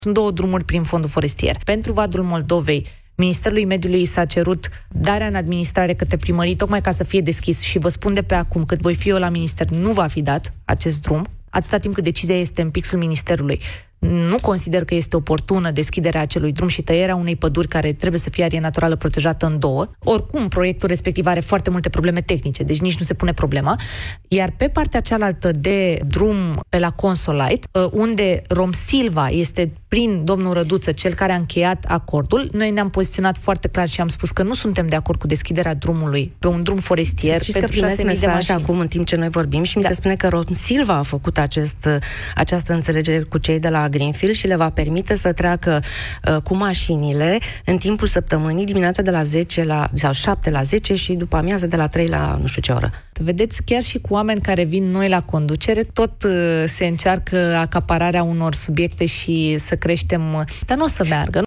Ministra Mediului, Diana Buzoianu, invitată la Misiunea Verde